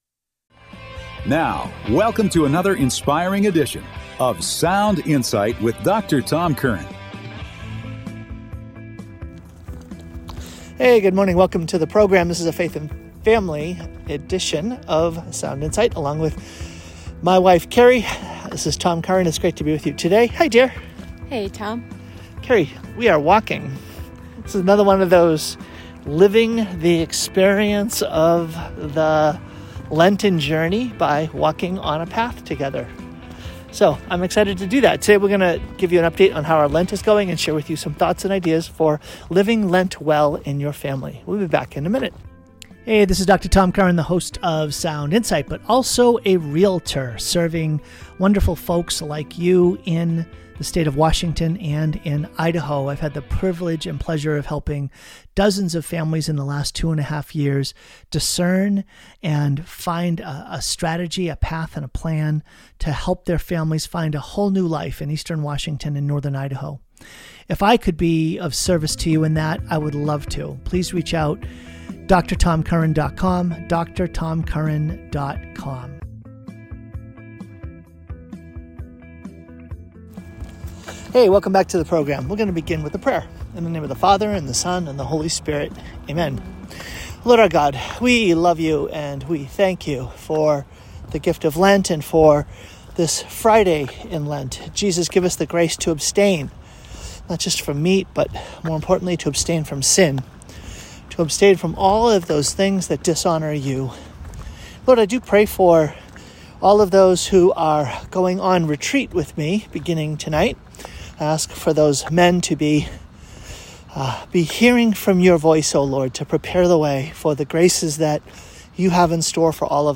LIVE with listeners